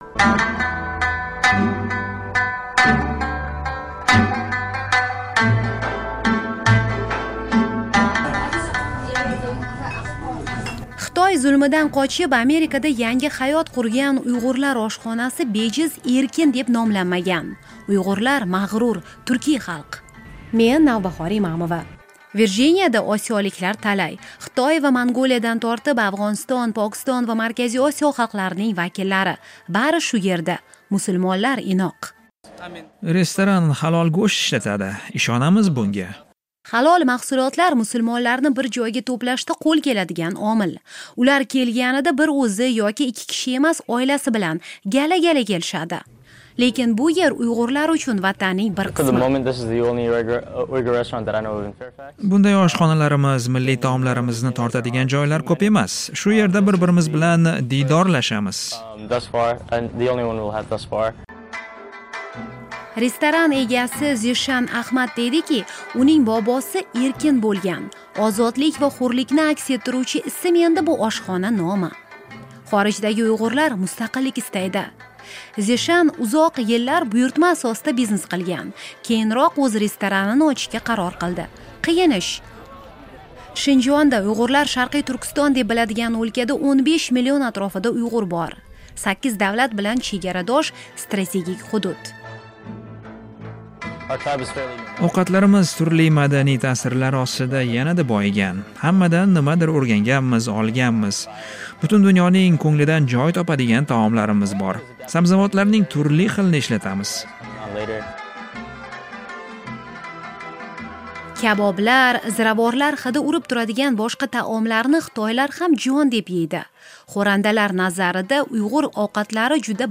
Virjiniya shtatidagi uyg'ur restoranidamiz